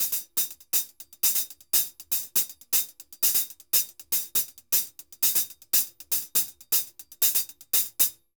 HH_Merengue 120_2.wav